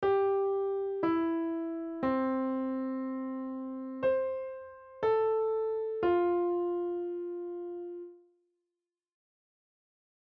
On the piano, play The Wheels On The Bus
G E C
>C A F